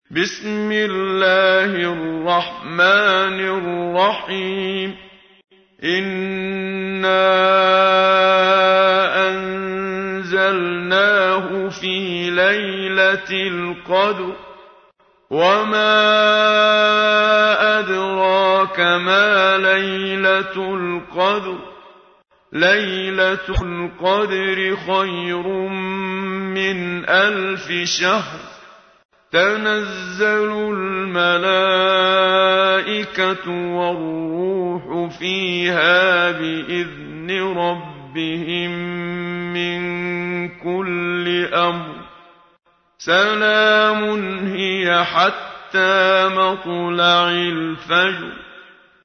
تحميل : 97. سورة القدر / القارئ محمد صديق المنشاوي / القرآن الكريم / موقع يا حسين